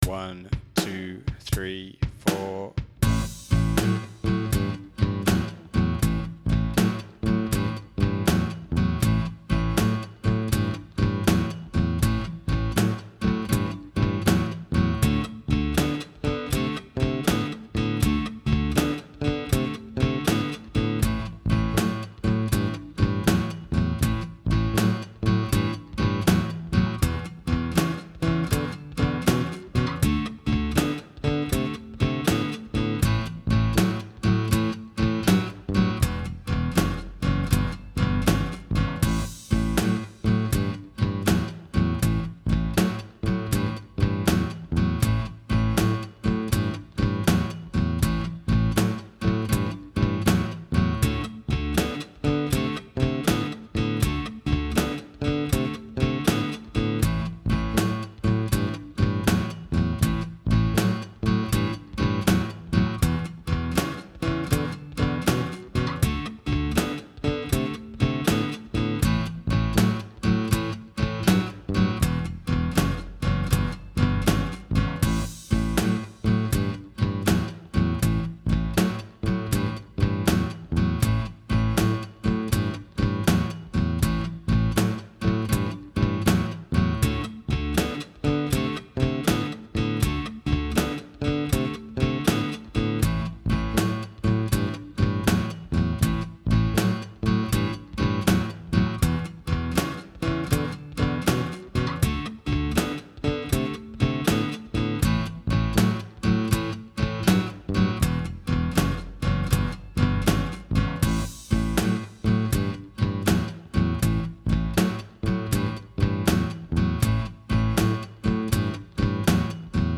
12 Bar Blues in A Backing Track | Download
bluesa_jam.mp3